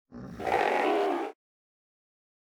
PixelPerfectionCE/assets/minecraft/sounds/mob/polarbear_baby/idle4.ogg at mc116